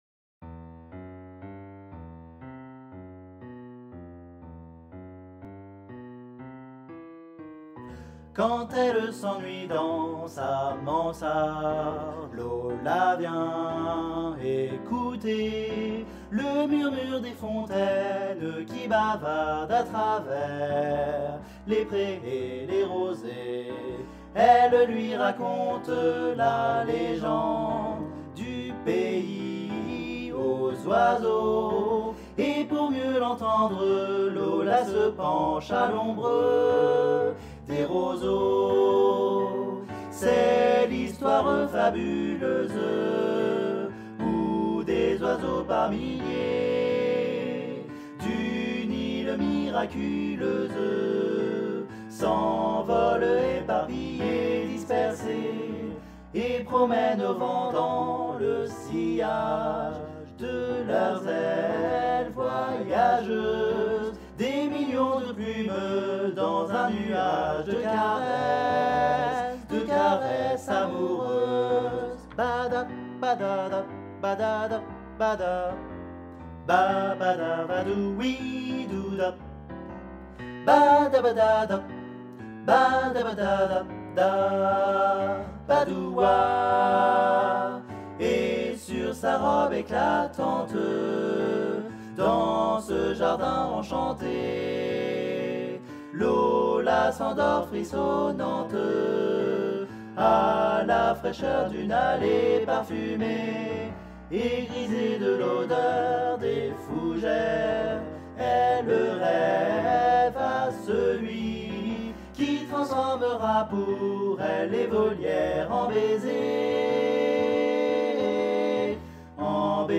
MP3 versions chantées
Guide Voix Tutti